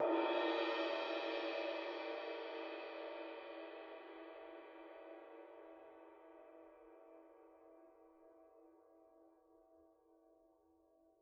susCymb1-hit_mp_rr1.wav